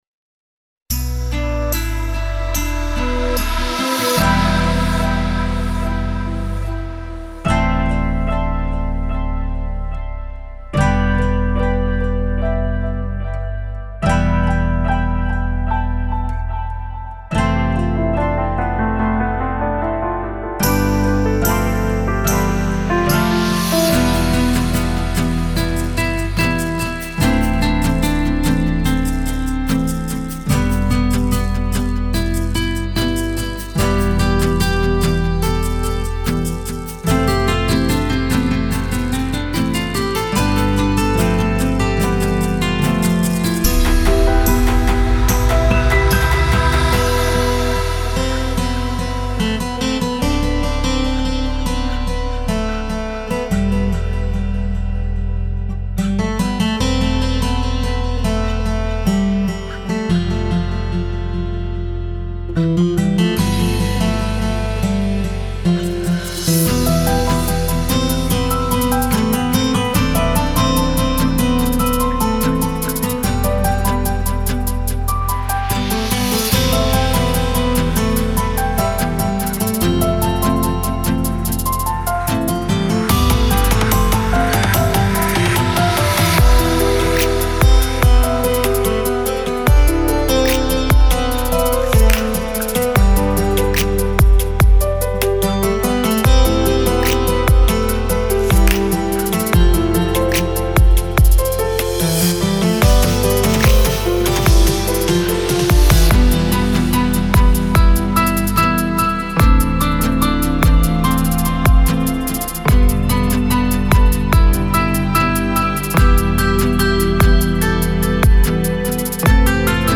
سبک آرامش بخش , موسیقی بی کلام
موسیقی بی کلام آرامبخش